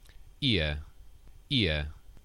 Haz clic para escuchar la pronunciación de las palabras: